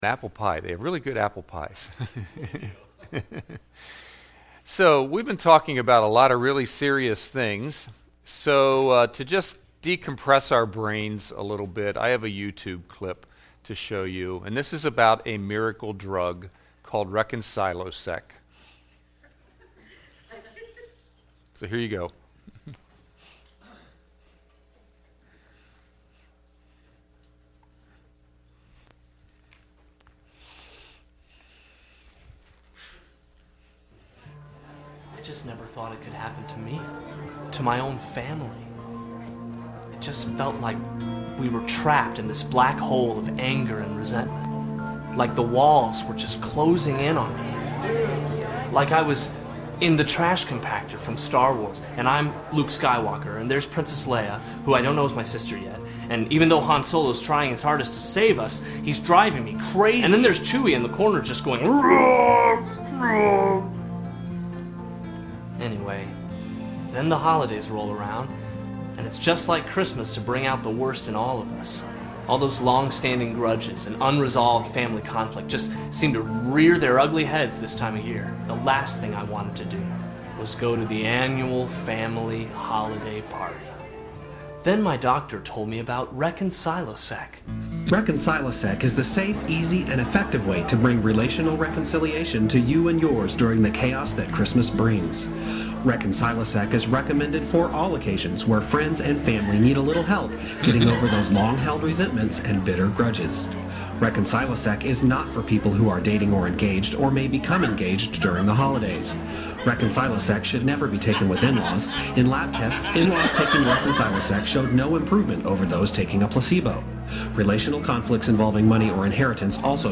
Guest Speaker Peacemaker conference